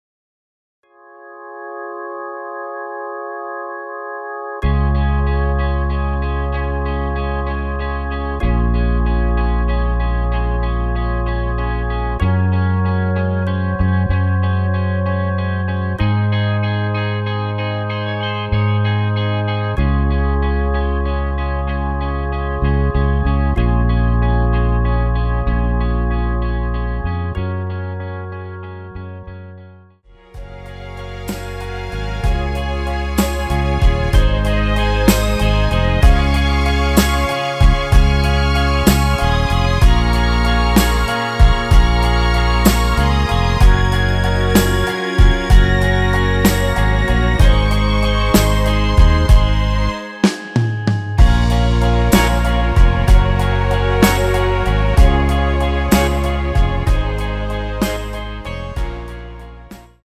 원키에서(+5)올린 여성분이 부르실수 있는 키의 MR입니다.
Db
앞부분30초, 뒷부분30초씩 편집해서 올려 드리고 있습니다.
중간에 음이 끈어지고 다시 나오는 이유는